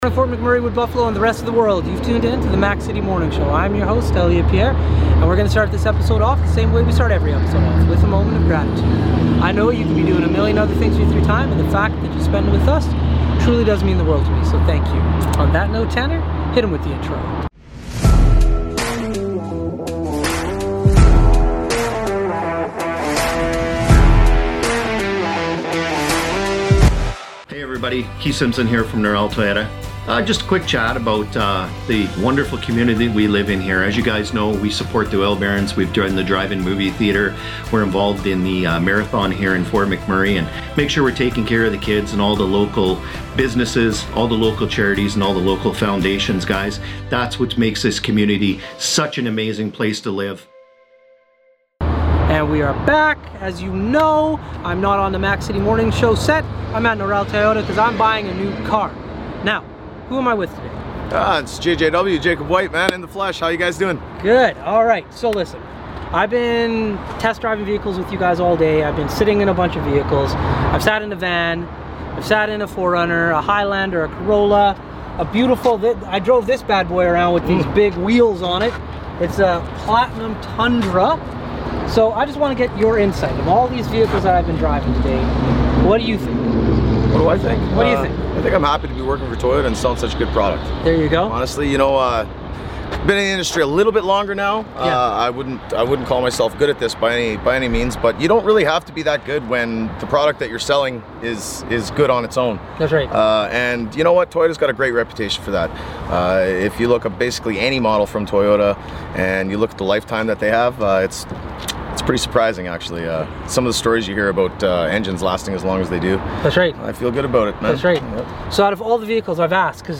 We are on location at Noral Toyota